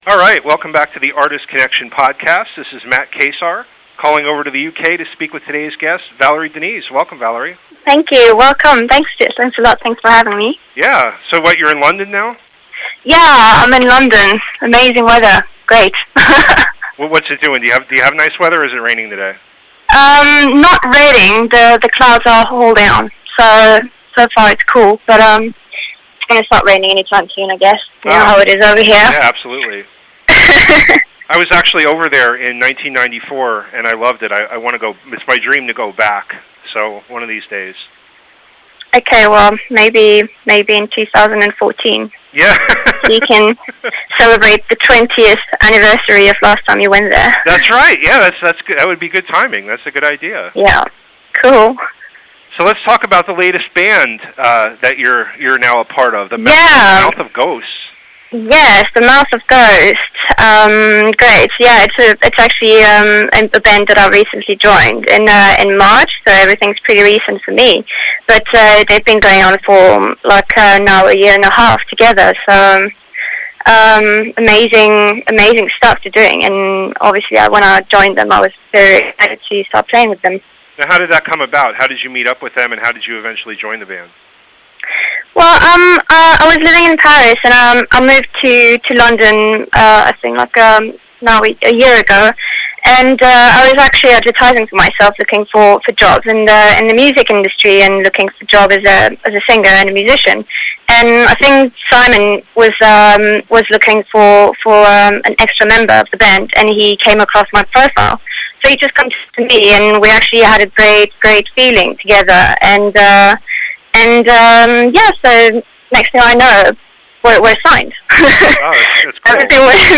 I recently called her on a fine London afternoon to talk about her life, the band, and her musical journey so far.
It is one of two songs featured on this episode.